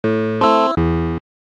Sample sounds, mostly quite short